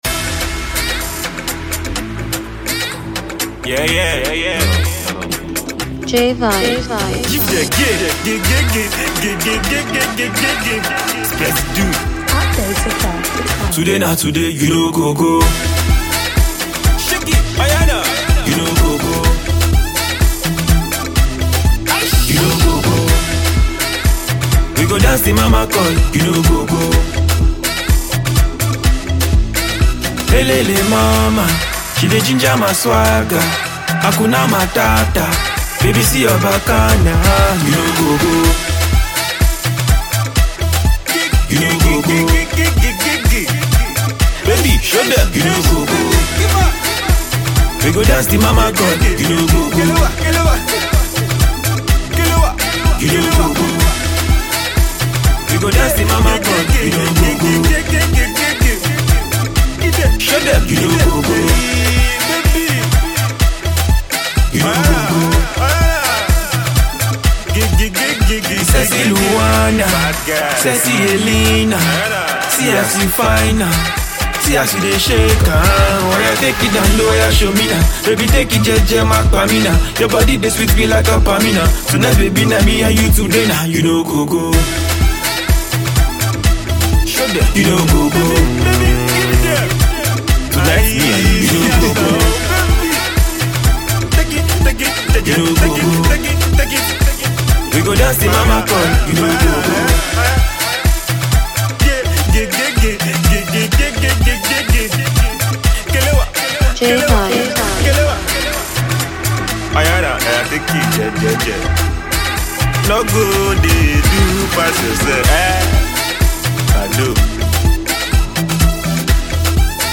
Genre: Hip hop, Rap